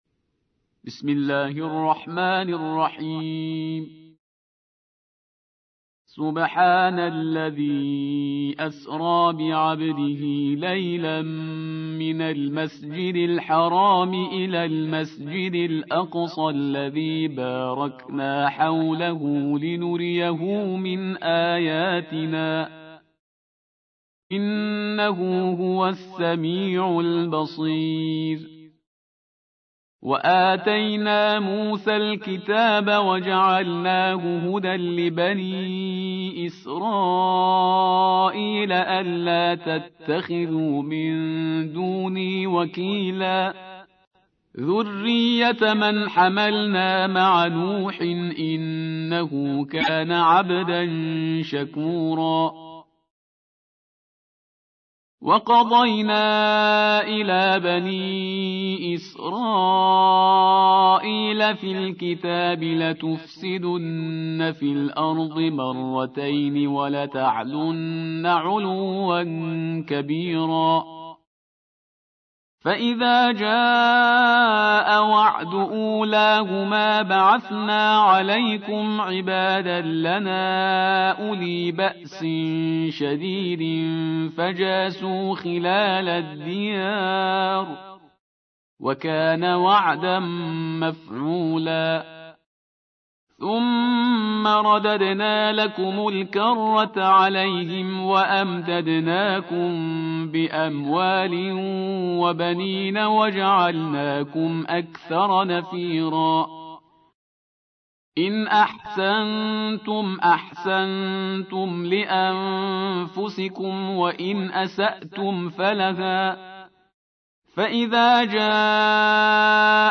17. سورة الإسراء / القارئ